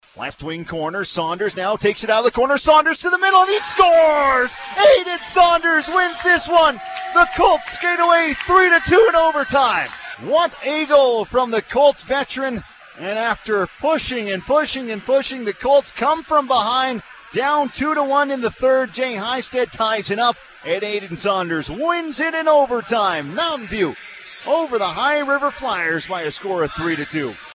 As heard on 96.5 CKFM.